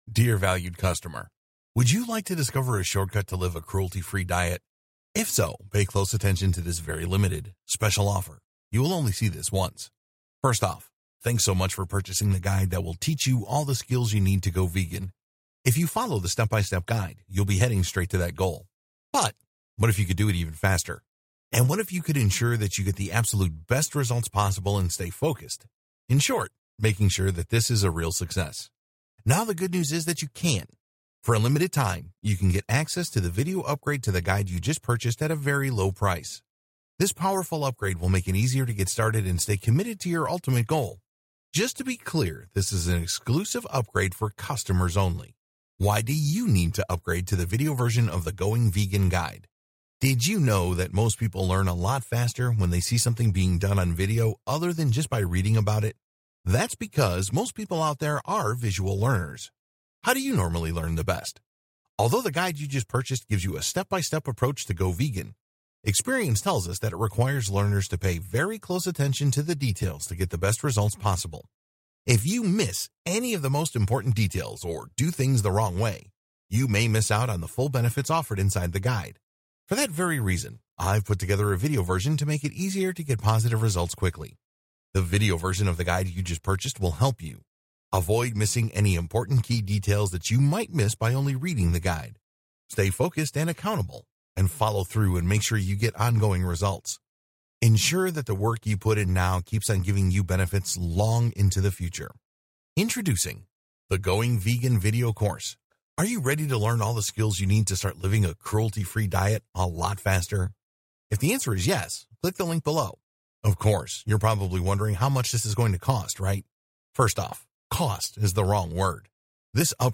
Upsell-Sales-Video-Voice-Over.mp3